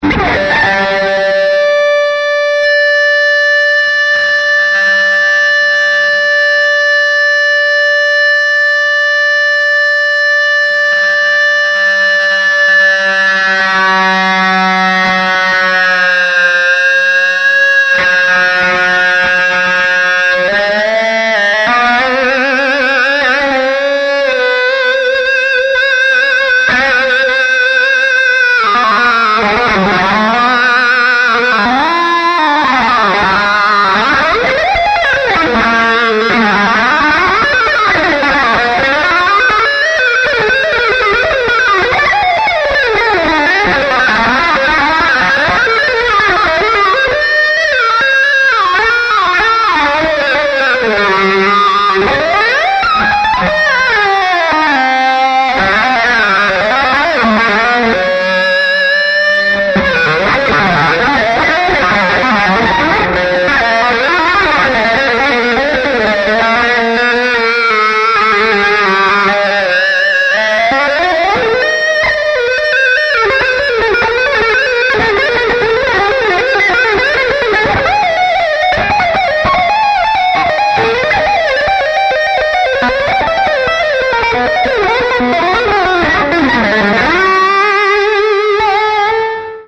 Solo guitar improvisation
Gibson ES335 guitar, Fender London Reverb amp, E-bow